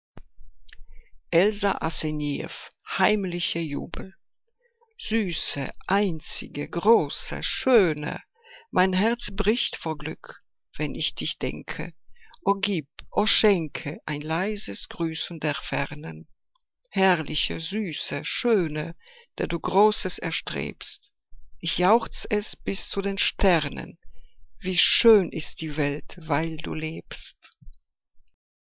Liebeslyrik deutscher Dichter und Dichterinnen - gesprochen (Elsa Asenijeff)